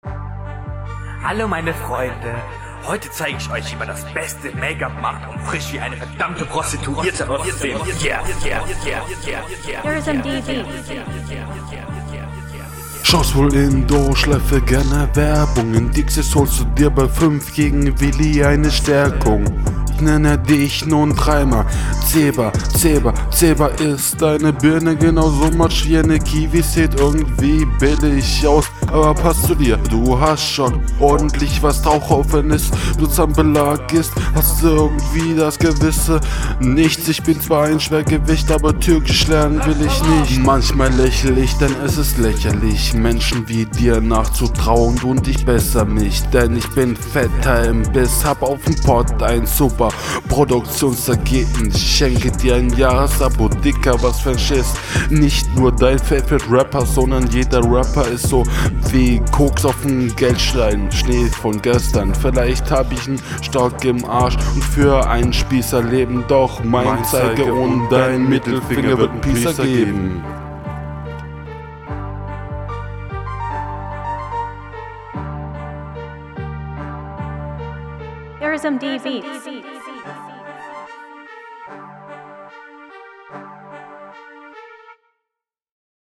Driveby Battle Schnelles Battle Format